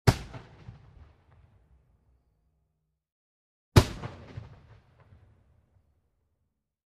Звуки петард
Взрыв петарды с отголоском — 1 вариант